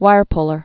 (wīrplər)